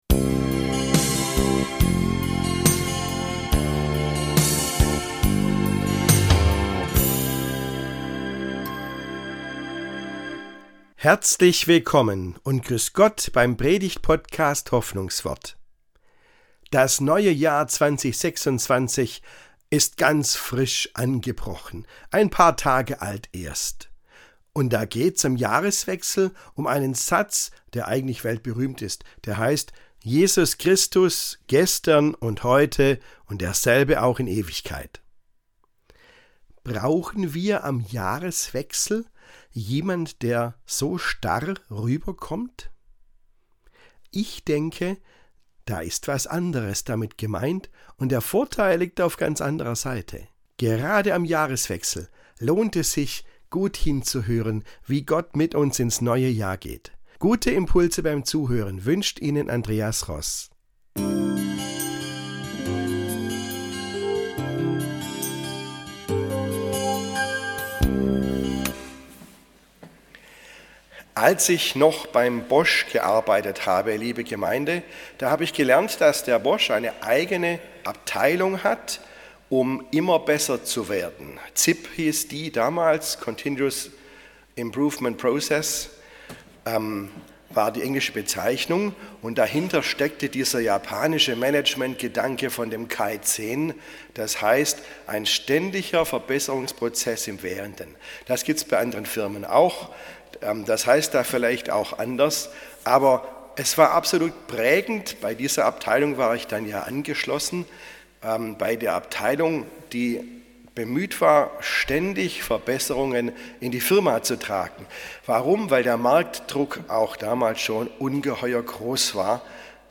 Mit Zuversicht ins neue Jahr ~ Hoffnungswort - Predigten